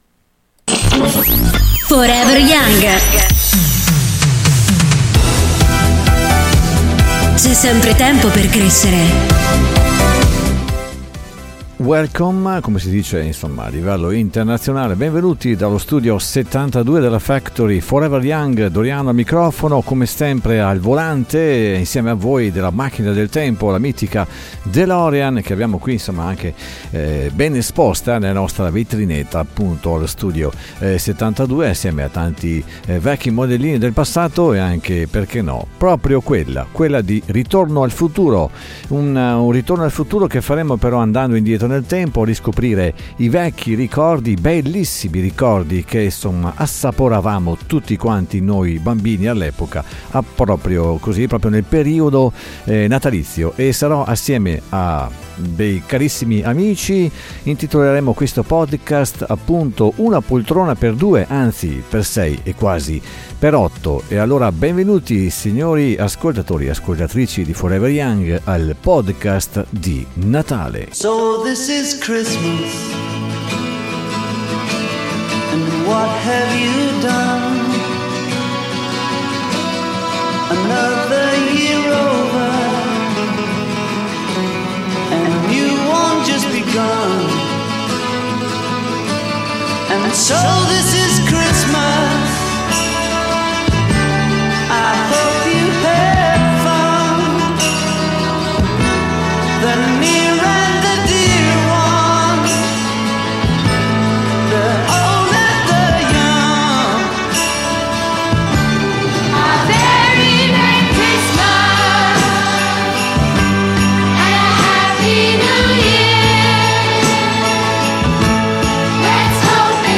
dallo studio 72 della Factory in compagnia di alcuni ospiti.